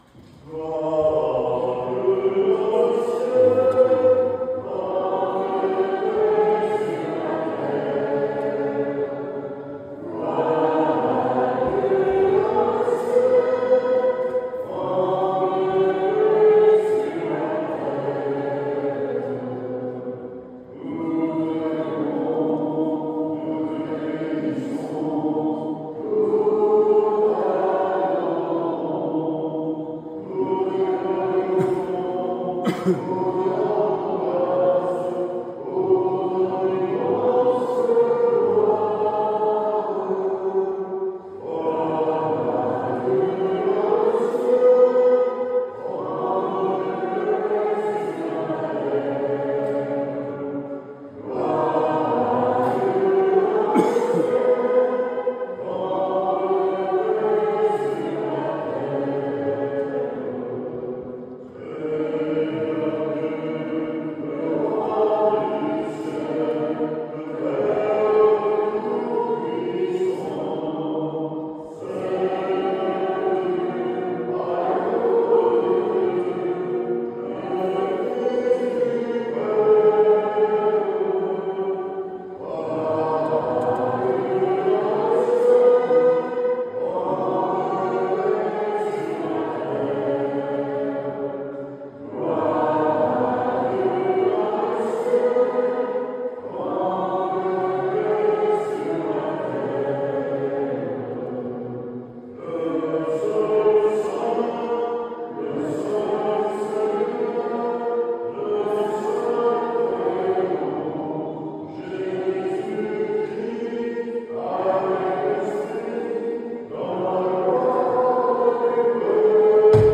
Une messe a eut lieu dans cette église le 15/08/2025 à 10H30
un cinquantaine de fidèles ont participé à cette office